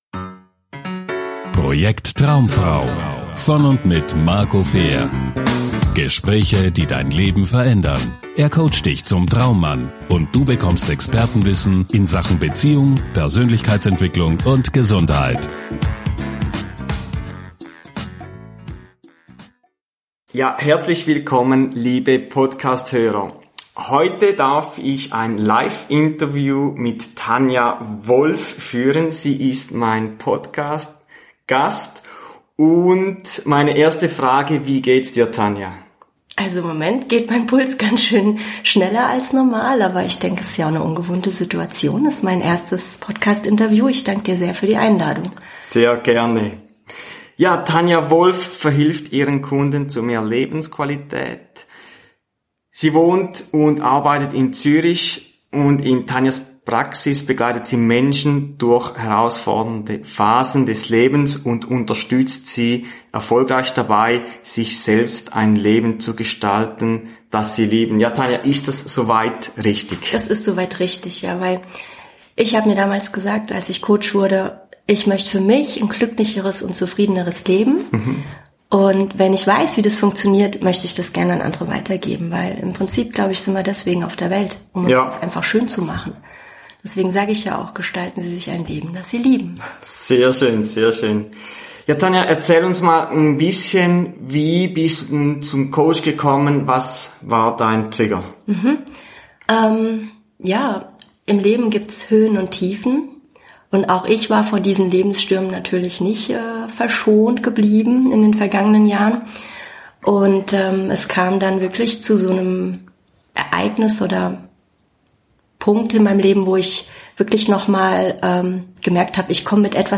Podcast Interview – Liebeskummer überwinden